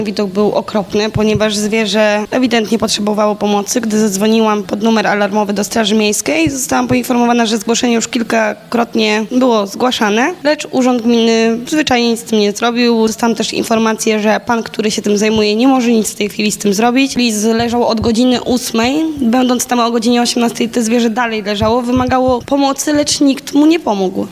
– mówi stargardzianka, która próbowała pomóc zwierzęciu.